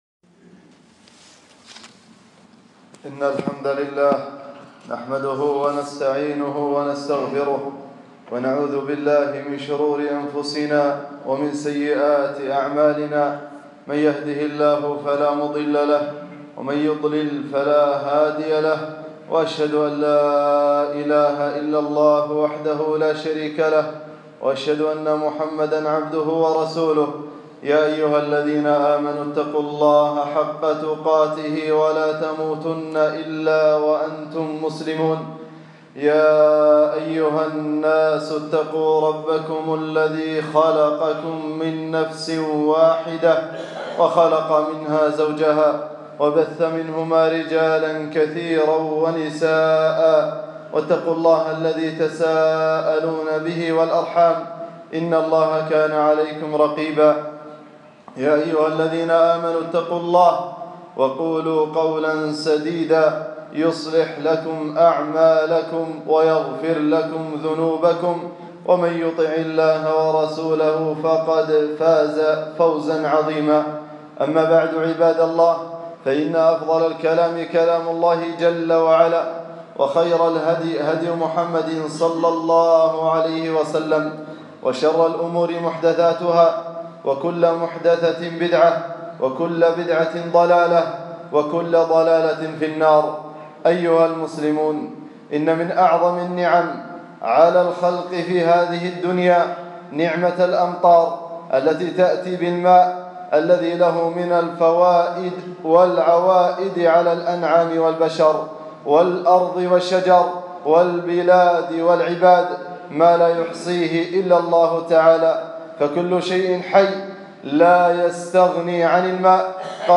خطبة - الأمطار نعمة من العزيز الجبار 8-3-1440 هــ